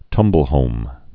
(tŭmbəl-hōm)